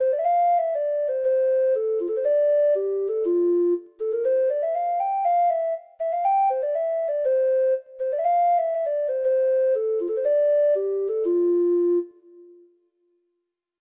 Treble Clef Instrument version